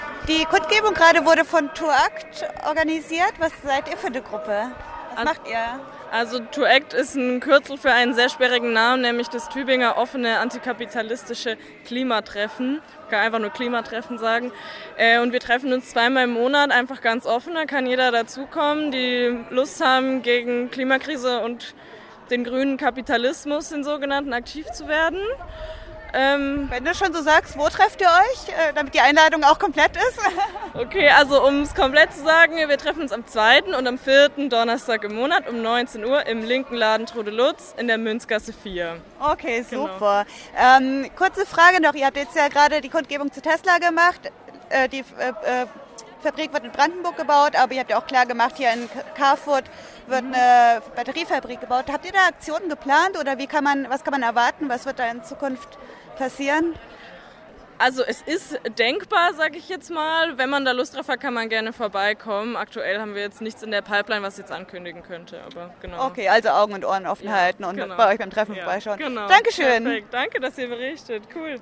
Hier findet ihr alle Redebeiträge der Kundgebung gegen Tesla am 10.5.2024 zum Nachhören.
5-Tesla_Einladung-ToAkt-Treffen.mp3